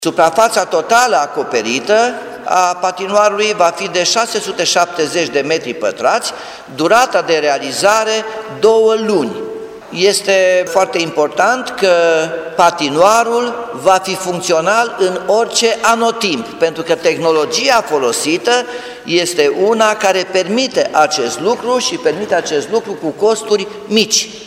Primarul Timișoarei spune că patinoarul va fi amplasat în Parcul Copiilor, în zona intrării dinspre bulevardul Revoluției.